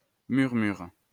wymowa:
(1) IPA/myʁ.myʁ/